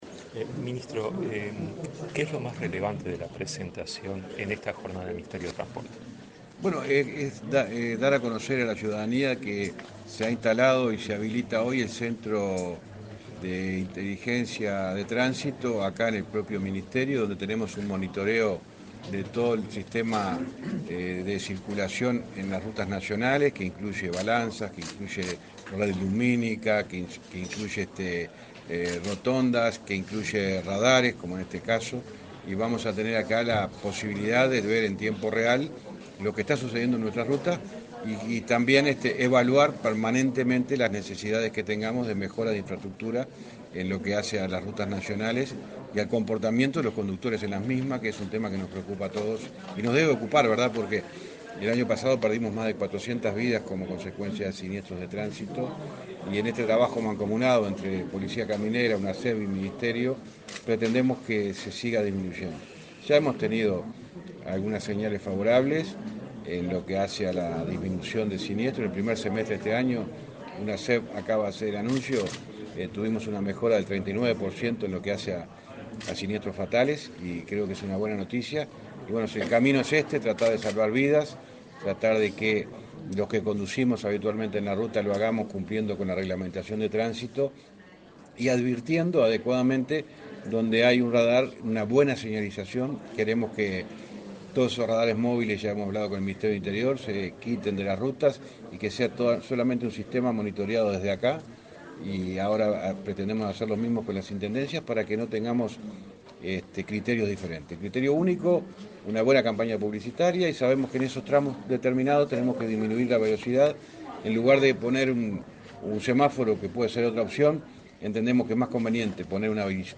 Entrevista al ministro de Transporte y Obras Públicas, José Luis Falero
Entrevista al ministro de Transporte y Obras Públicas, José Luis Falero 07/08/2023 Compartir Facebook X Copiar enlace WhatsApp LinkedIn Tras participar en el lanzamiento del Centro de Sistemas Inteligentes de Tránsito, este 7 de agosto, el ministro de Transporte y Obras Públicas, José Luis Falero, realizó declaraciones a la prensa.
Falero prensa.mp3